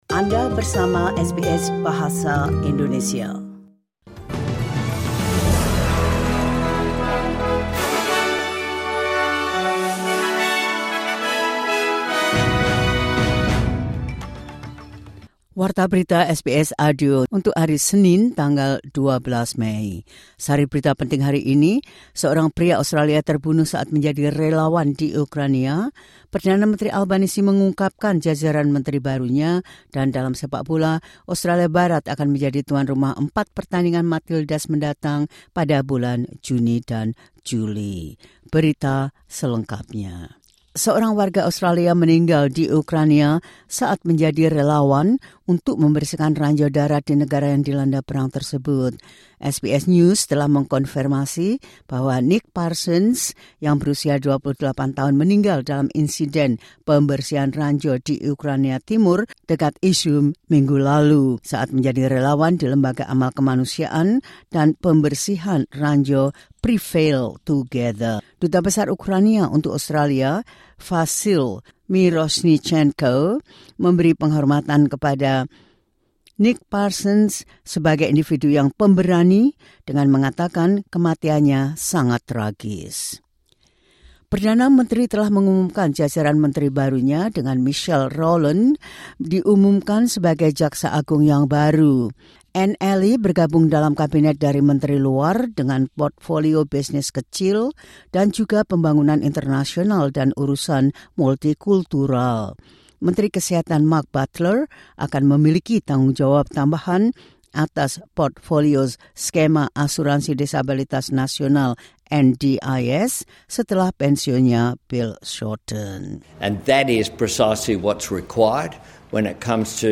The latest news SBS Audio Indonesian Program – 12 May 2025.